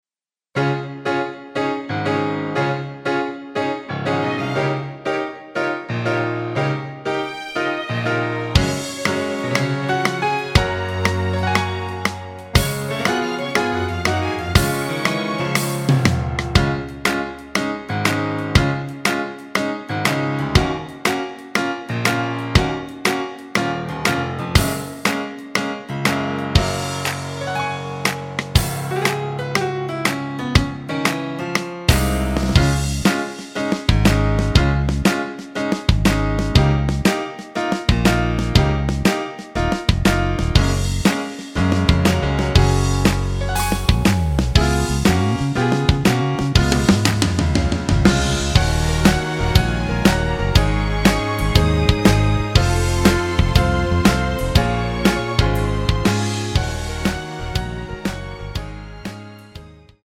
Db
앞부분30초, 뒷부분30초씩 편집해서 올려 드리고 있습니다.
중간에 음이 끈어지고 다시 나오는 이유는
곡명 옆 (-1)은 반음 내림, (+1)은 반음 올림 입니다.